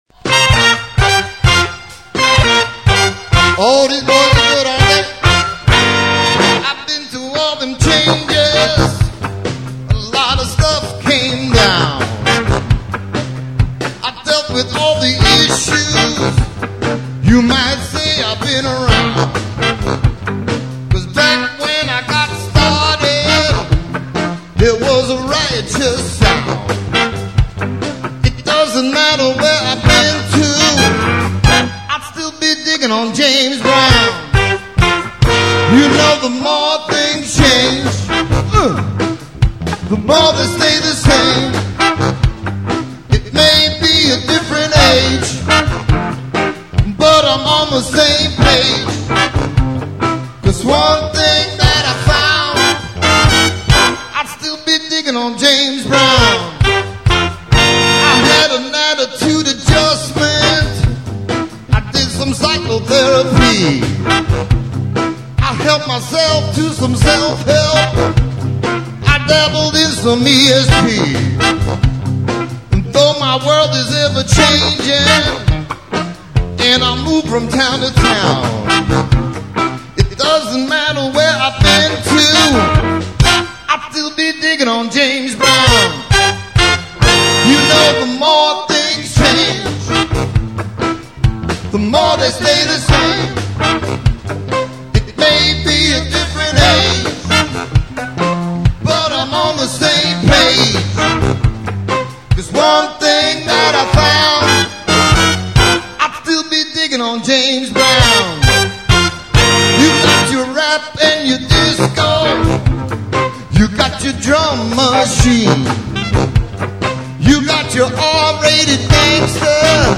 R&B, Soul, and Funk<
Roger Sherman Baldwin Park Greenwich, CT
( 2 trk live recording)